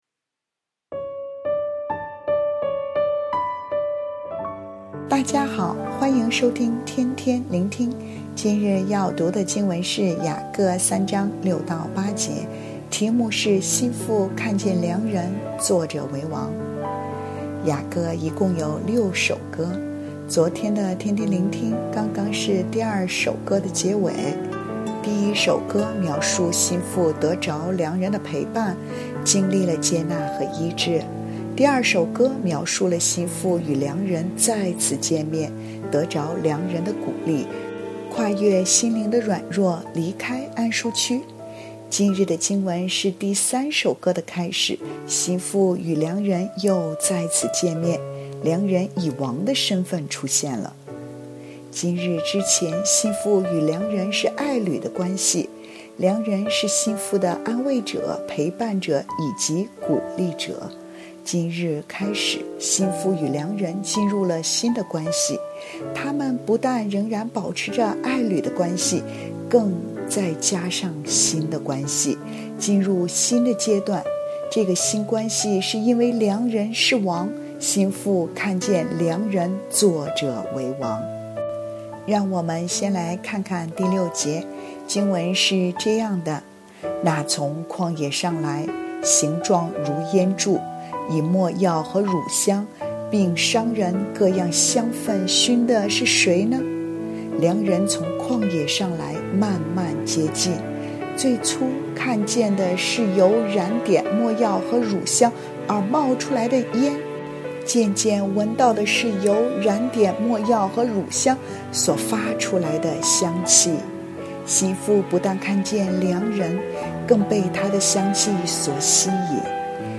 普通話錄音連結🔈